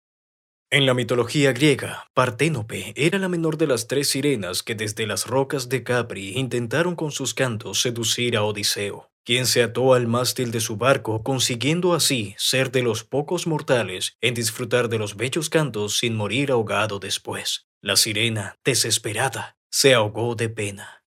Latin American male voice overs